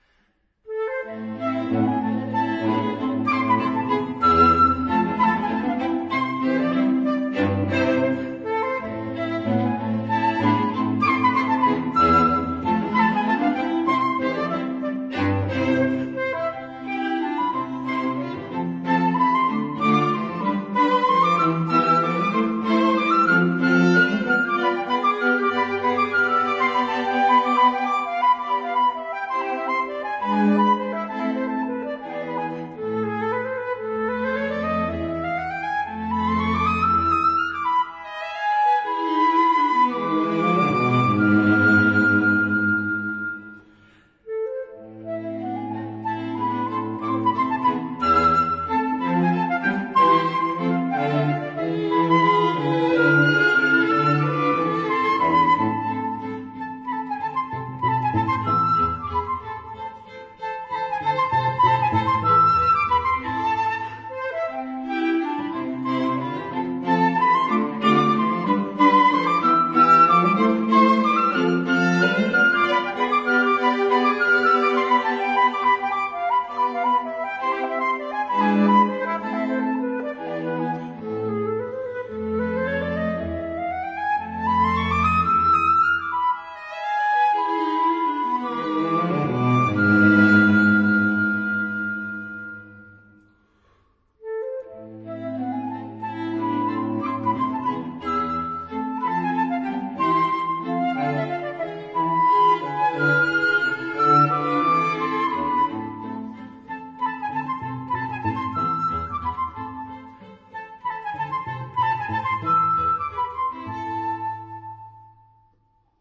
他雖是義大利作曲家，但這個長笛四重奏，聽起來卻很德奧。
優美、詩意的旋律